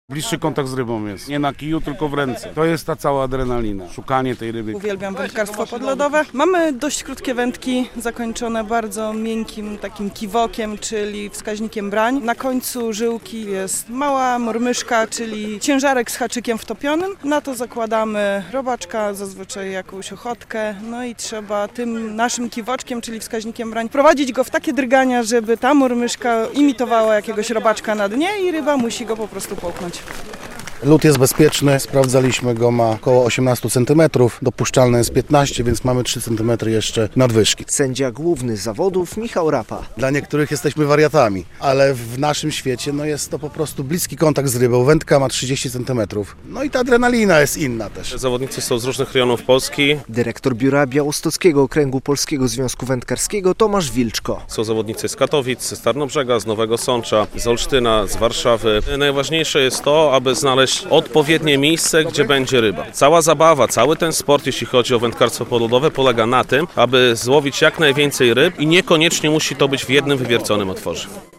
Podlodowe Mistrzostwa Polski na Zalewie Siemianówka, 1.03.2025, fot.
relacja